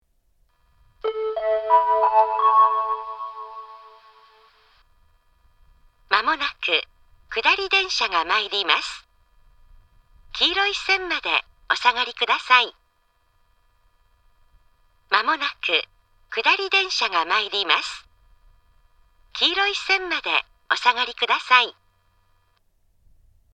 スピーカーはすべてユニペックスマリンです。
仙石型（女性）
接近放送
仙石型女性の接近放送です。
周辺駅より遅れて放送更新しているので、放送が新しいです。